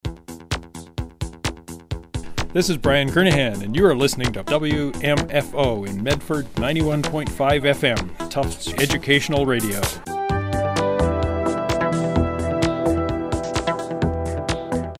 BrianKernighan_WmfoStationID_Kraftwerk_ComputerWorld_15s.mp3